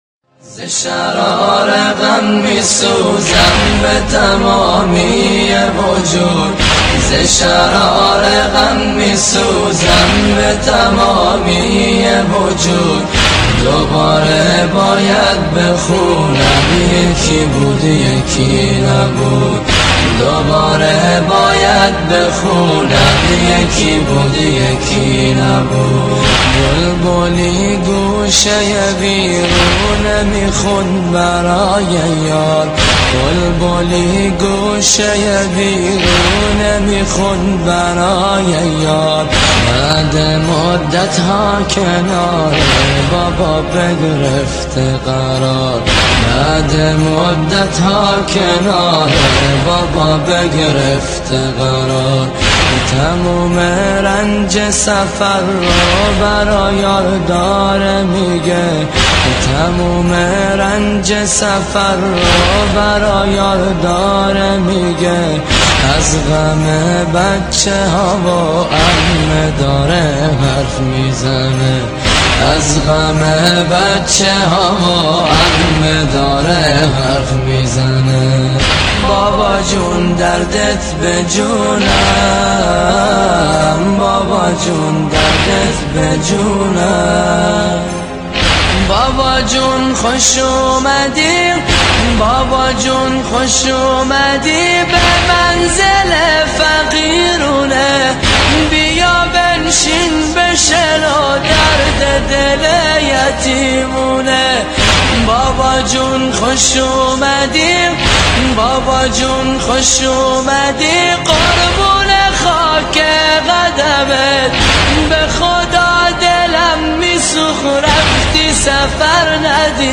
• دانلود نوحه و مداحی